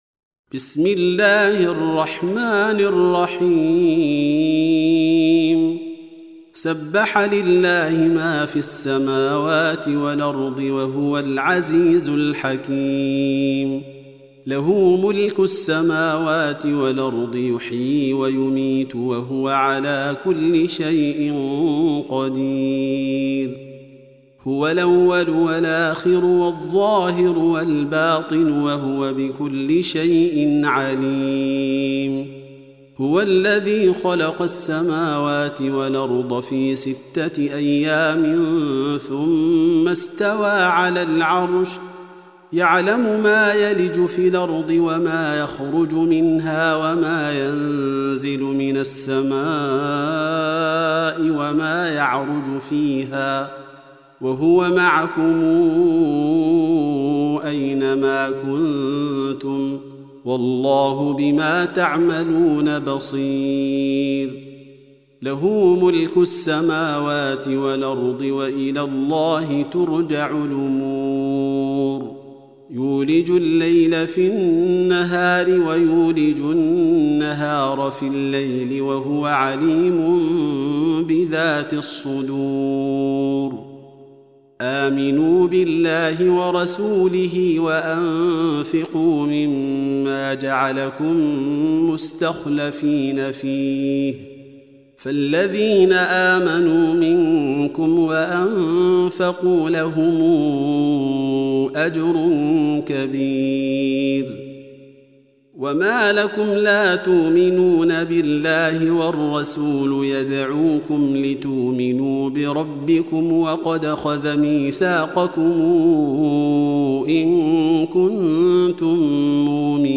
Surah Sequence تتابع السورة Download Surah حمّل السورة Reciting Murattalah Audio for 57. Surah Al-Had�d سورة الحديد N.B *Surah Includes Al-Basmalah Reciters Sequents تتابع التلاوات Reciters Repeats تكرار التلاوات